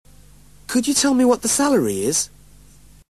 PITCH IN BRITISH ENGLISH
After listening to each audio file, repeat it aloud trying to imitate the intonation:
JOB APPLICANT TO INTERVIEWER